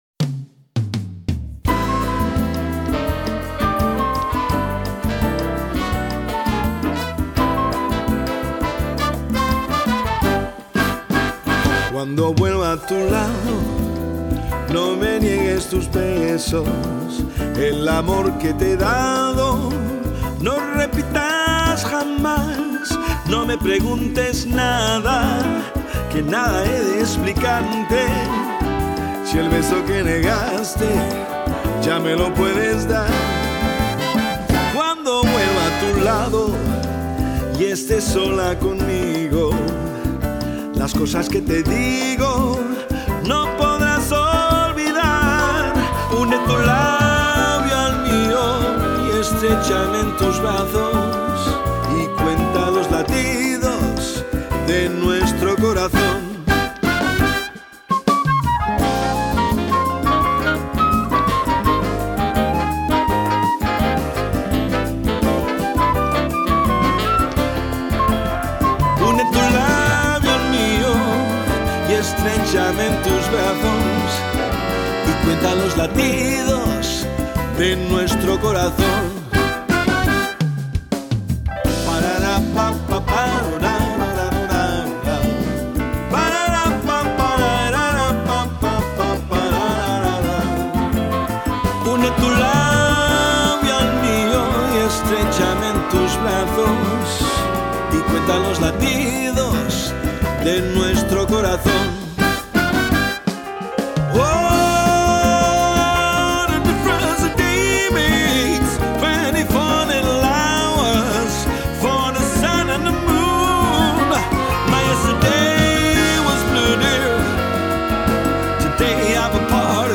Abcouder Feestweek met Sonny's Inc. Wat was het druk en wat een sfeer!